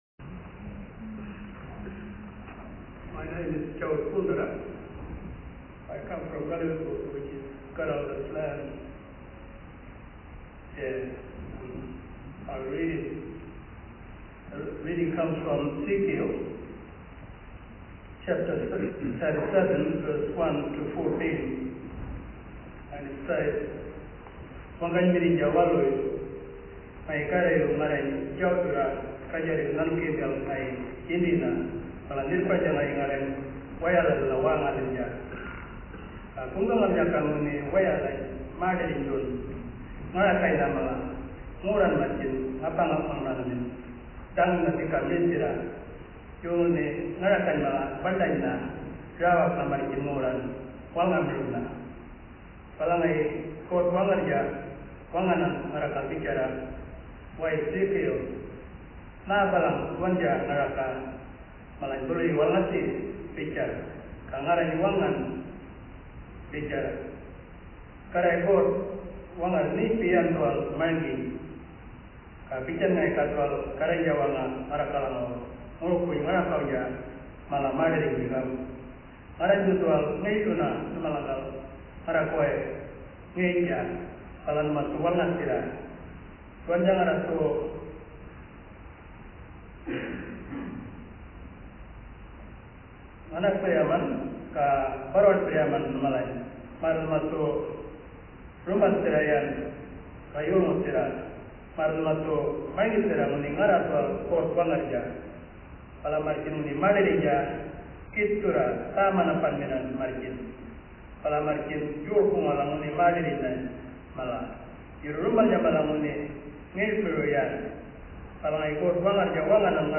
The 4th day of the 15th Assembly began with worship led by the Northern Synod. It included the reading of Ezekiel 37:1-14; the vision of the valley of dry bones, a prophecy of outrageous confidence in the LORD, expressing a hope that could liberate a people in exile from their good homeland, the promised gift of God.
It was the first public reading of this new translation.